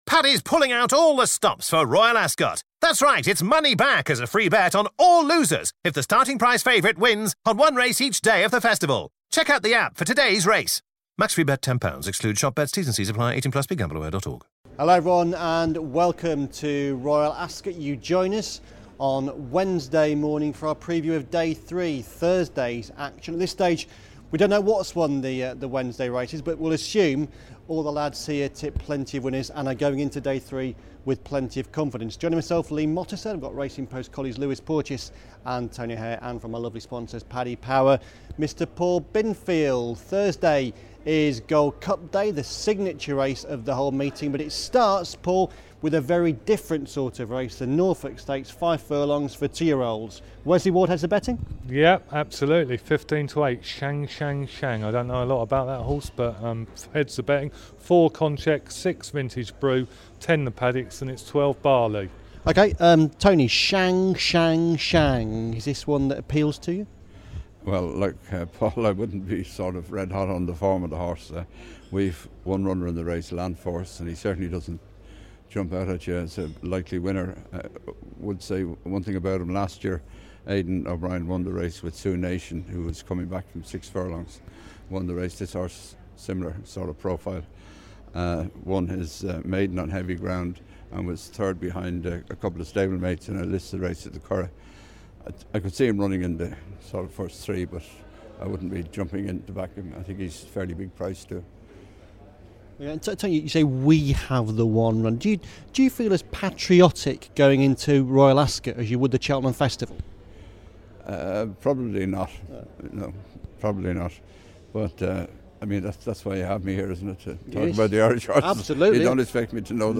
chairs proceedings from the track